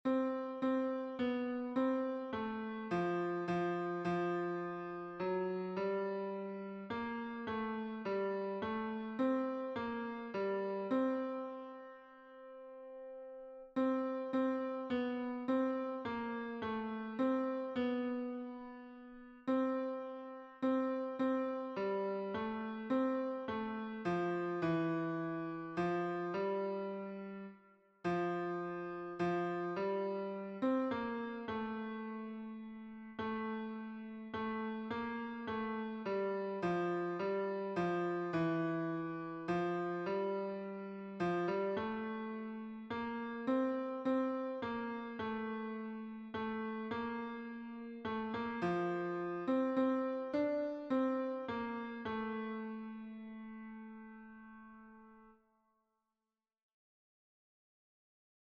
Turn Your Eyes upon Jesus — Tenor Audio.
Words and music by Helen H. Lemmel, 1922 Tune: LEMMEL Key signature: F major (1 flat
Turn_Your_Eyes_Upon_Jesus_tenor.mp3